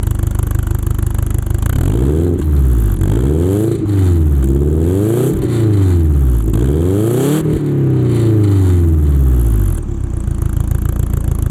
これを付けると、低音がカットされて街乗りは大変静かになります。
インナーサイレンサー付き 573KB